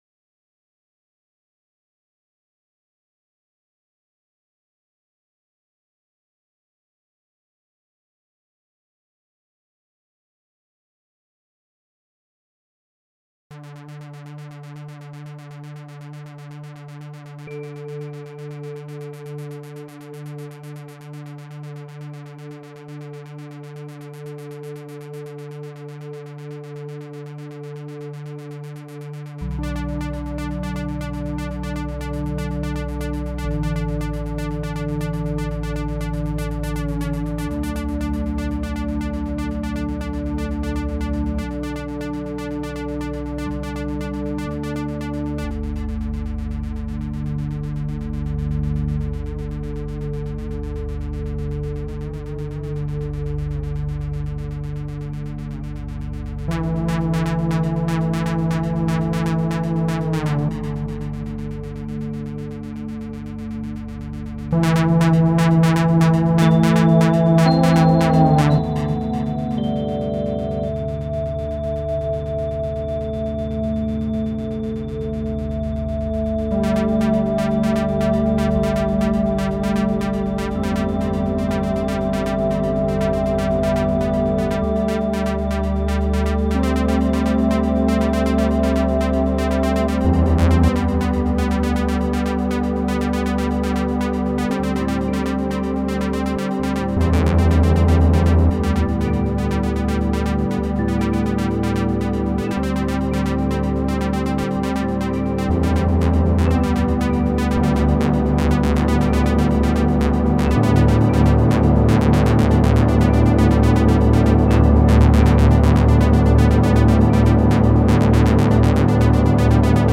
First solo album -- experimental.